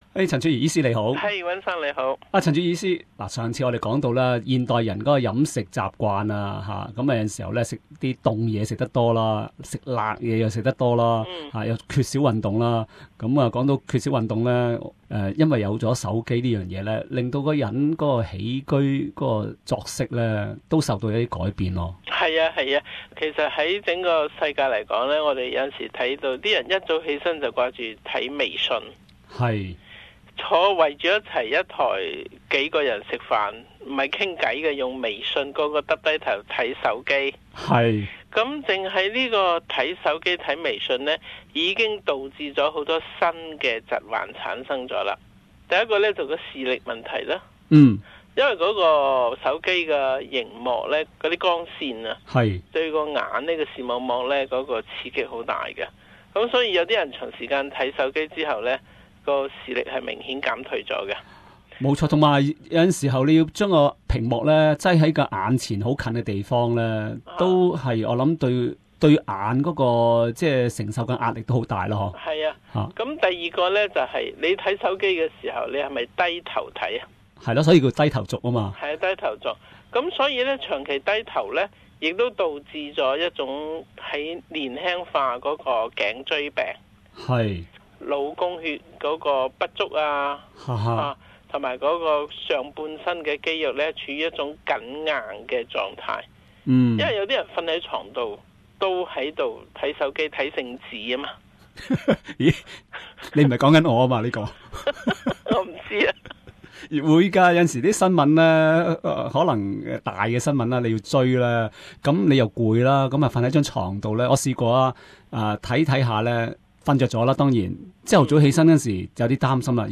a Chinese medicine practitioner, explains how weak digestion could be improved by a heated bag of Chinese medicines